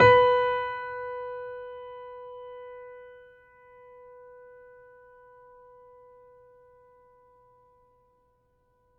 pianoSounds